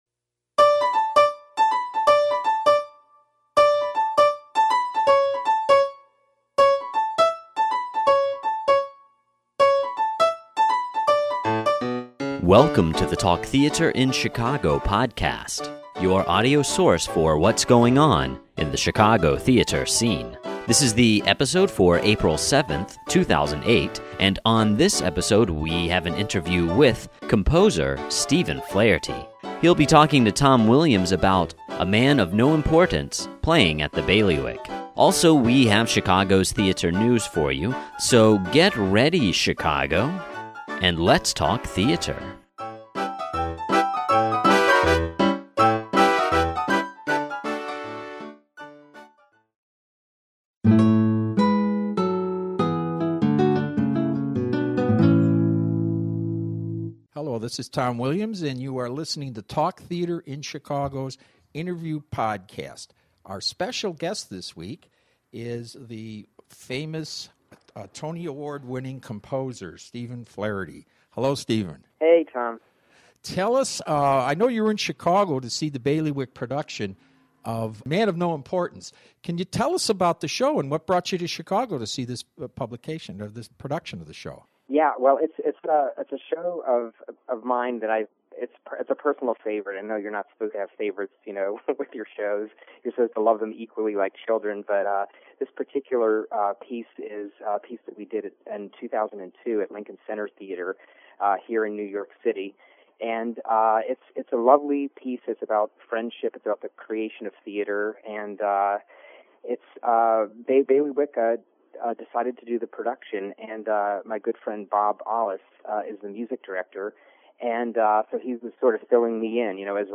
Stephen Flaherty Interview Podcast
An interview with Tony Award winnning composer Stephen Flaherty. He talks about his show A Man Of No Importance playing at the Bailiwick Repertory, as well as Ragtime in which he won a Tony Award.